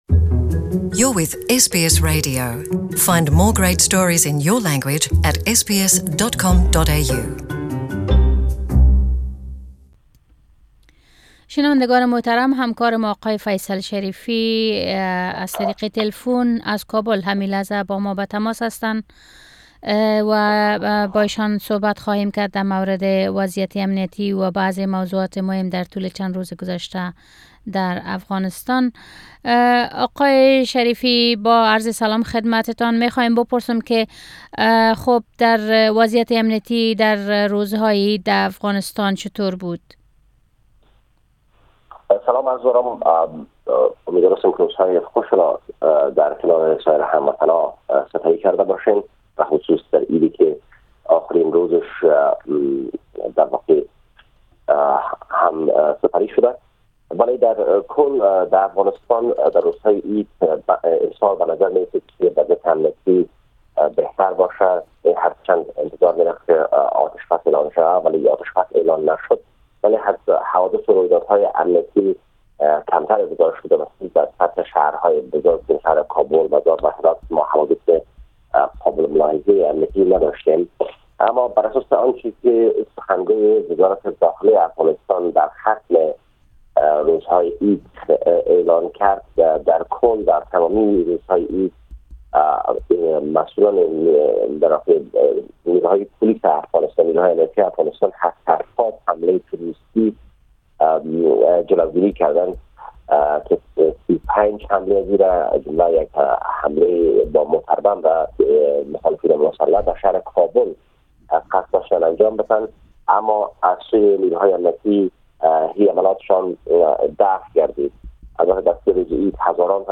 Our reporter